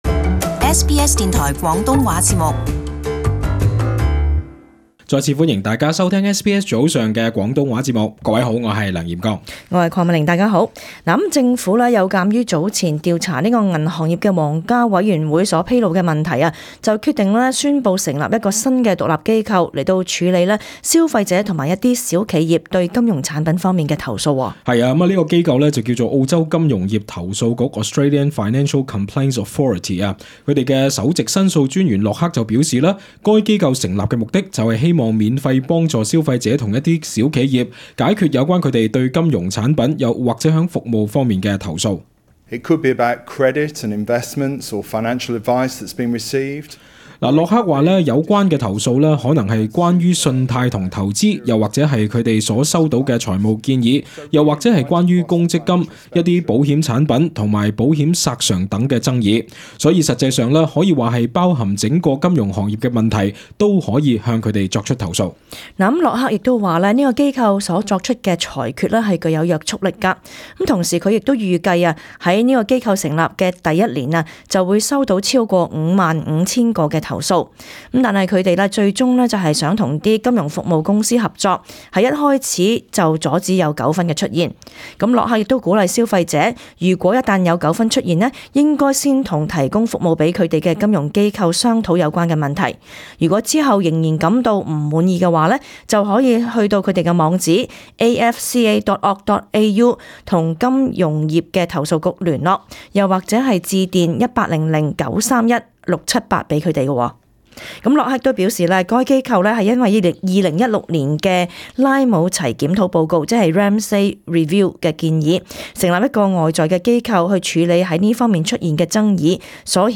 【時事報導】澳洲金融業產品今起投訴有門